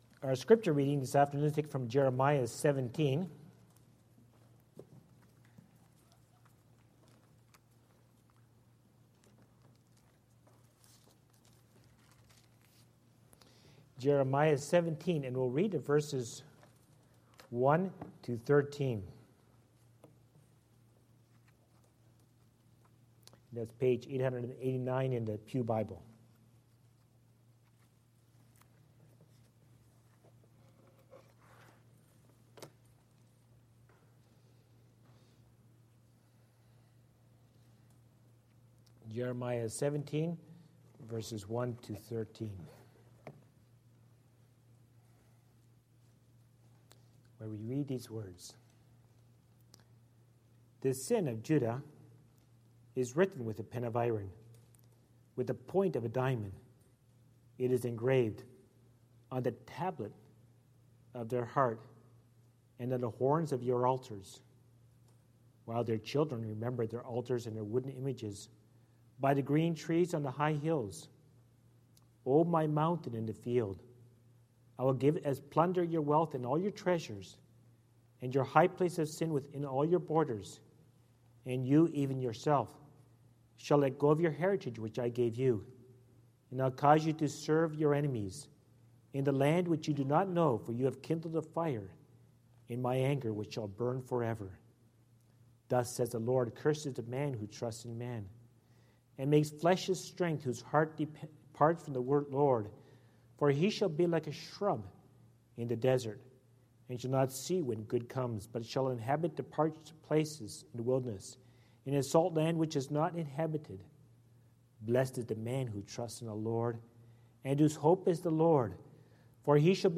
Our Daily Bread | SermonAudio Broadcaster is Live View the Live Stream Share this sermon Disabled by adblocker Copy URL Copied!